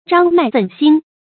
張脈僨興 注音： ㄓㄤ ㄇㄞˋ ㄈㄣˋ ㄒㄧㄥ 讀音讀法： 意思解釋： 謂血管膨脹，青脈突起。